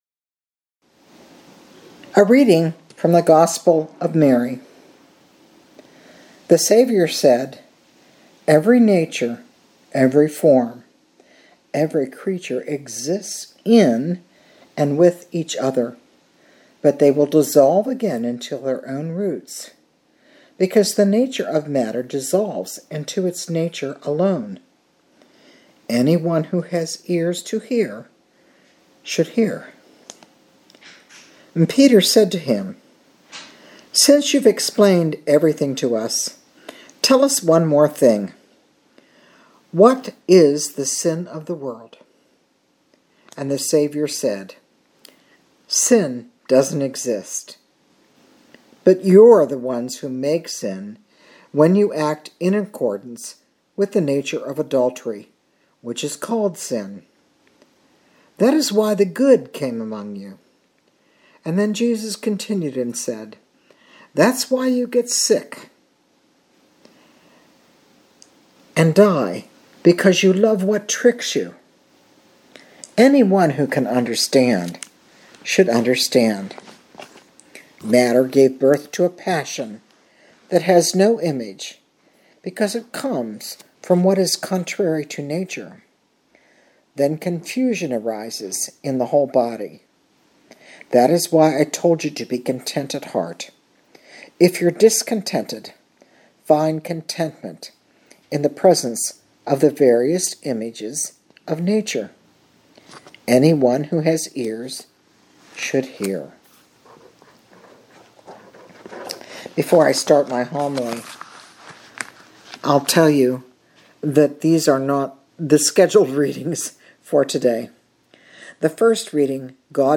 Living Beatitudes Community Homilies: Being Human